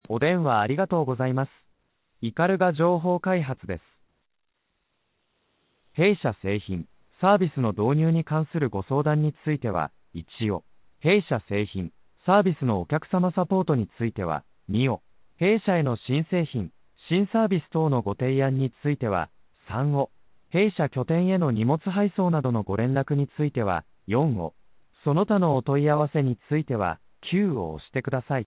電話自動音声応答システム(IVR)は顧客からの電話を受けると、次のようなAI合成音声による自動案内を行います。
サンプル音源(IVR)